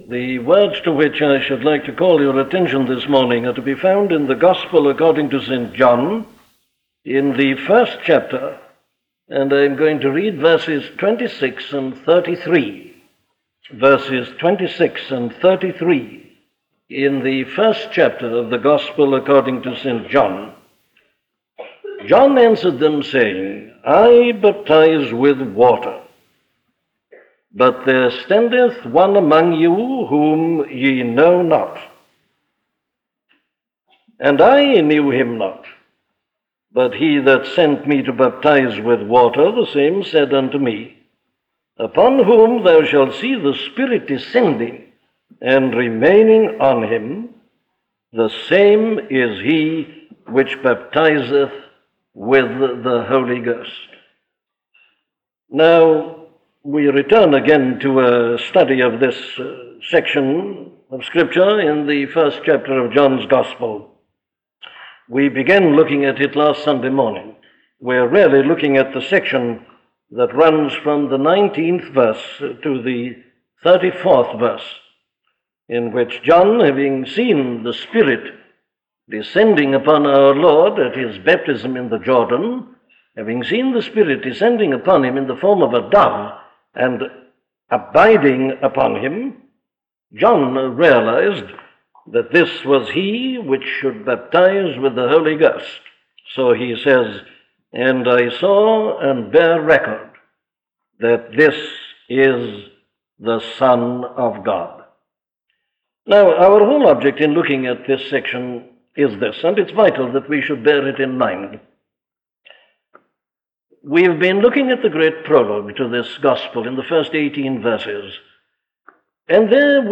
Second-hand Religion - a sermon from Dr. Martyn Lloyd Jones
In this sermon “Second-hand Religion,” Dr. Martyn Lloyd-Jones preaches from John 1:26–33. He tells his audience that no one can merely receive the fullness of Christ from the tradition of religion.